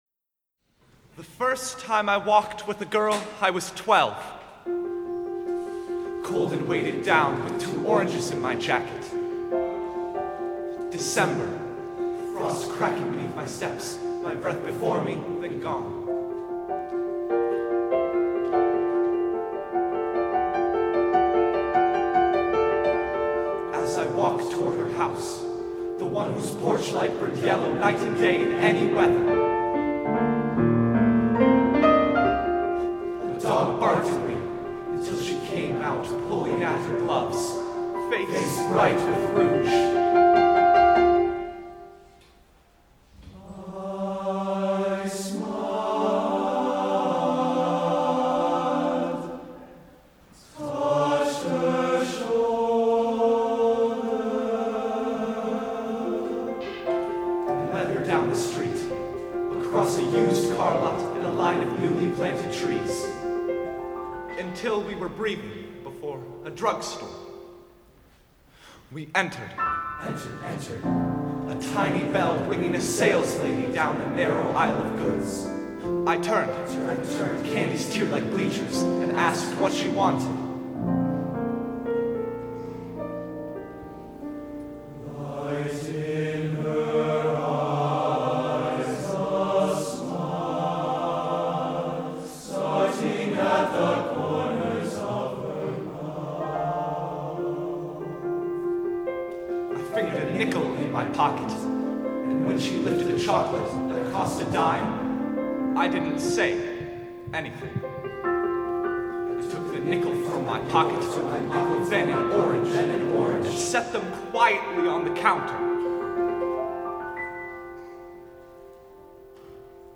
SSA or TBarB Choir, Solo, and Piano